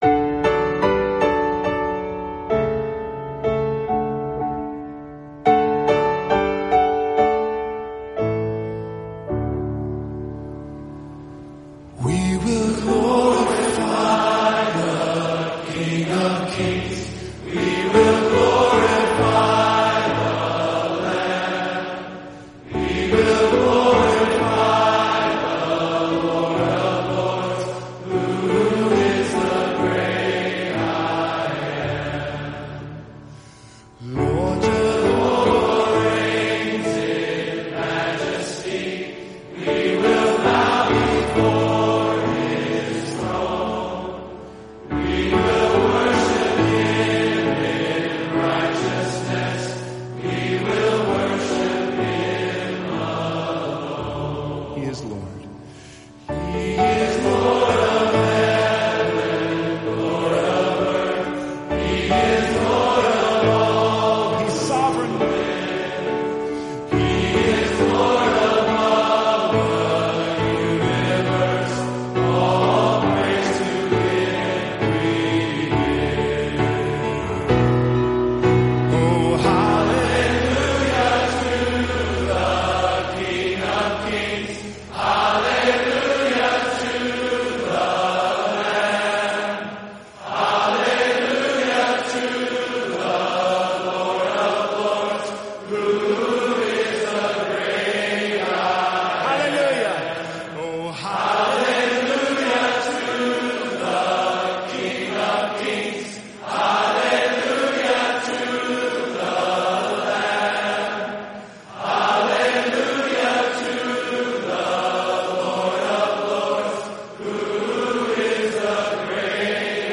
conductor.